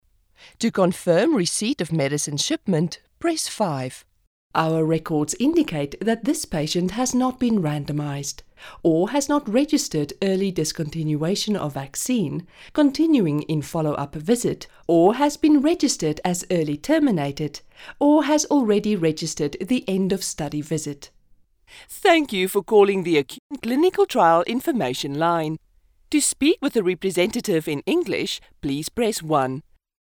Female
Approachable, Authoritative, Confident, Conversational, Corporate, Gravitas, Natural, Reassuring, Warm
South African
My voice has been described as warm, earthy, sensual, clear and authoritative.